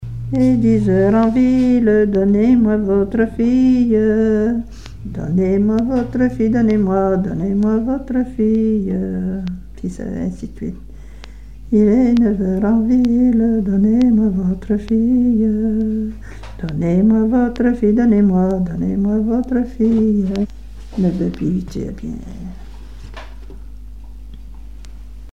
Mémoires et Patrimoines vivants - RaddO est une base de données d'archives iconographiques et sonores.
Genre énumérative
Répertoire de chansons traditionnelles et populaires
Pièce musicale inédite